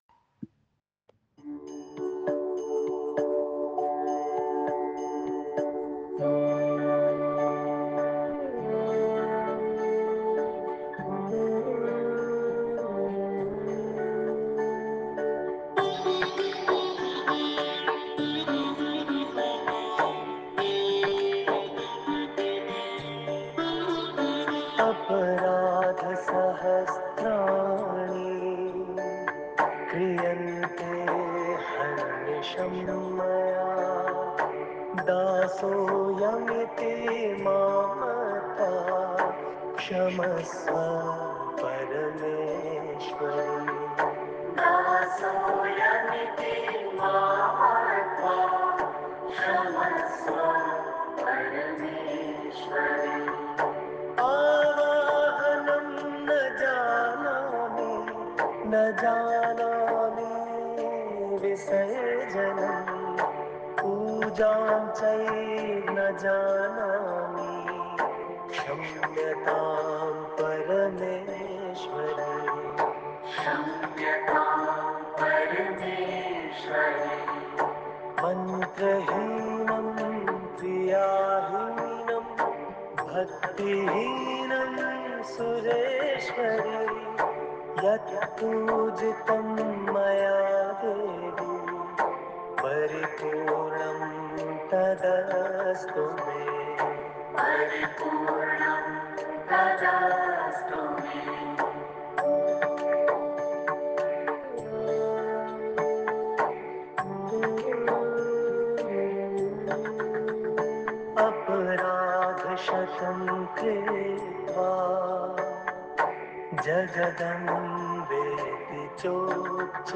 Forgiveness prayer.mp3